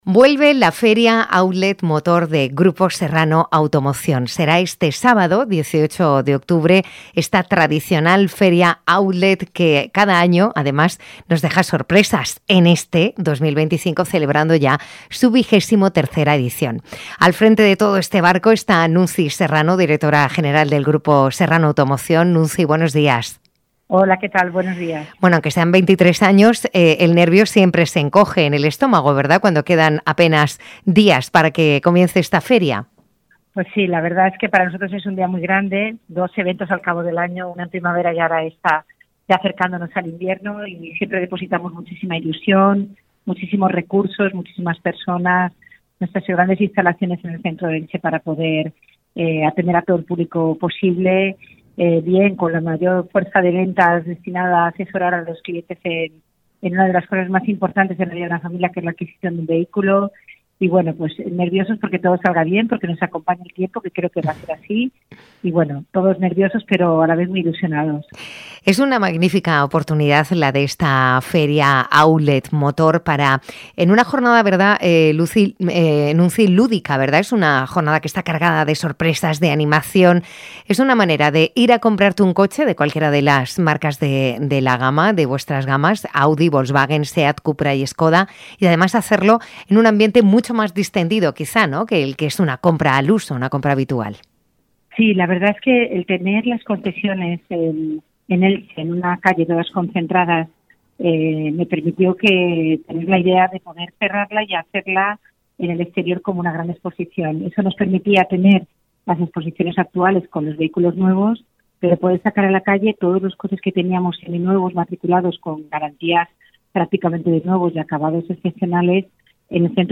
Hemos mantenido una charla